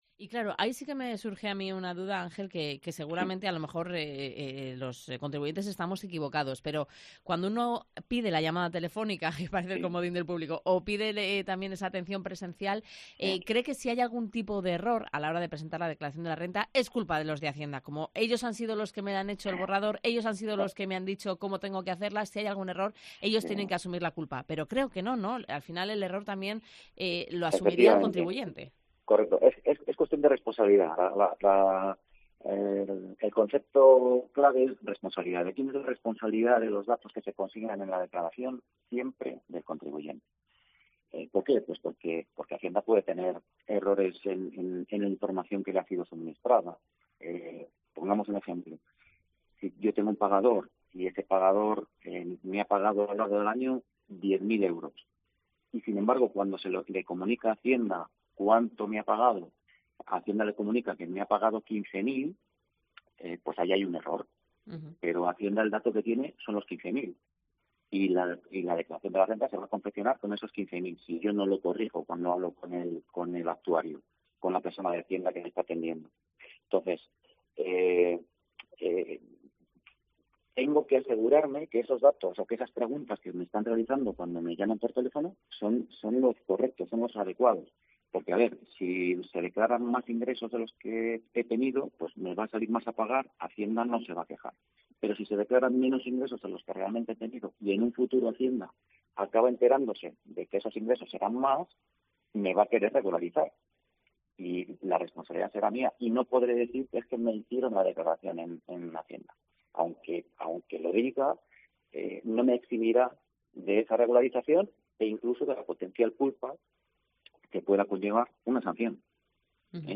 Valladolid - Tordesillas - Íscar